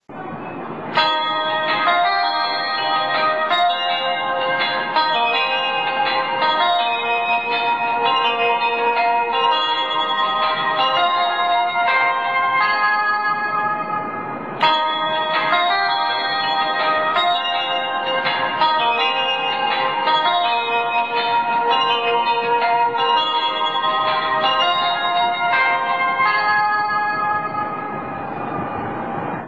ＪＲ広島駅の列車入線メロディーです。自動放送の後に２度流れます。
・１、５番線入線メロディー